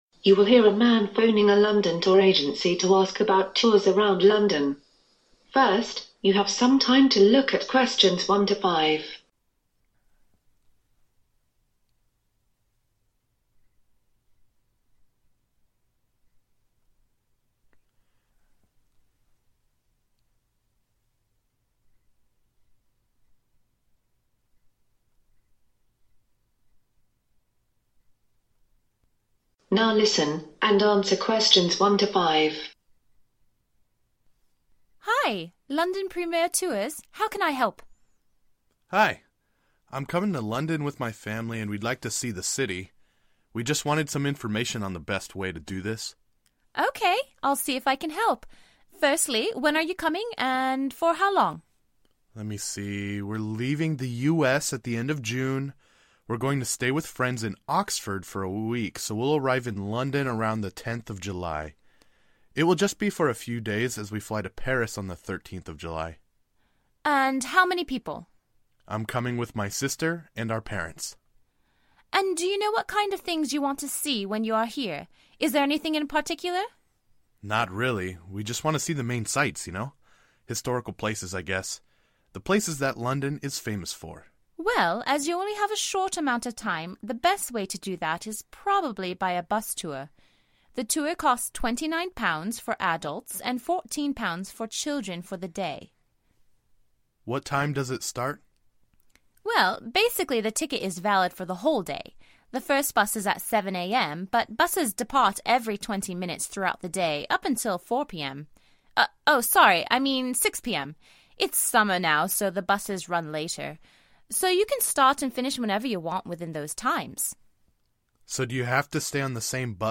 You will hear a tourist talking to a London Tour Company in order to organise a tour of the popular sights of London.